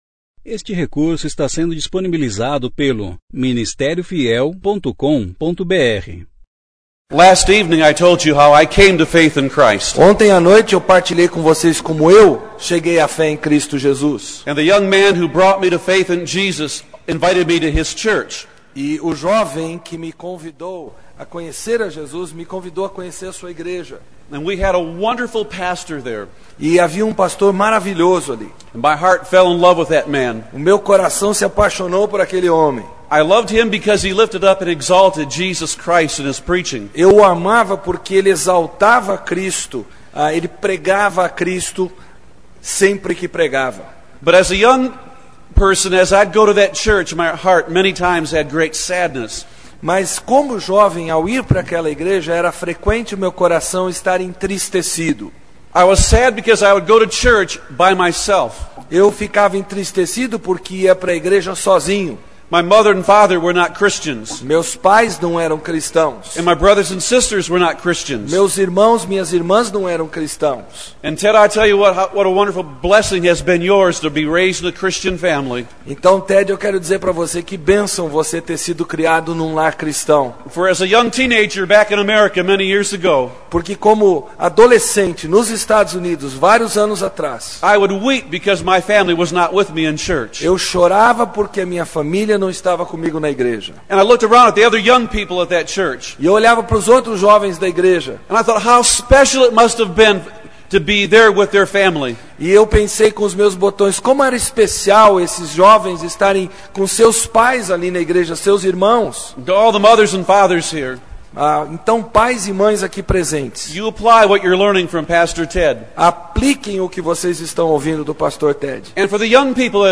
23ª Conferência Fiel para Pastores e Líderes – Brasil - Ministério Fiel